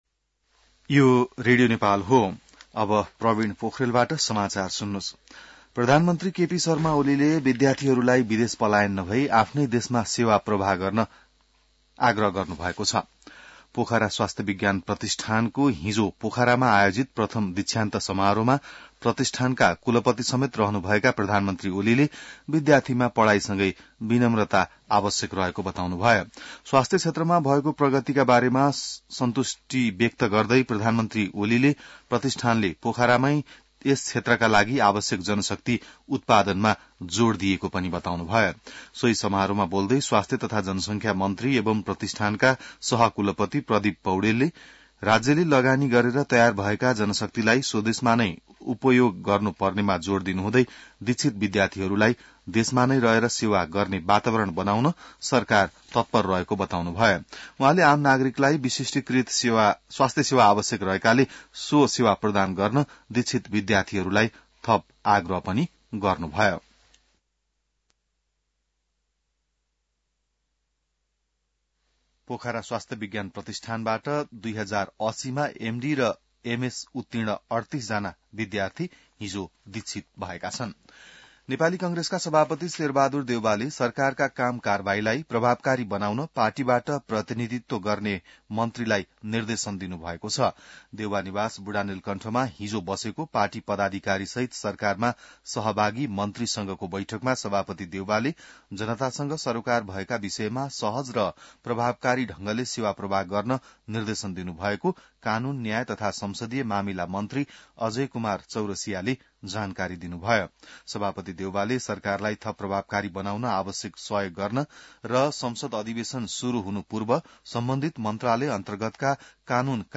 बिहान ६ बजेको नेपाली समाचार : ५ माघ , २०८१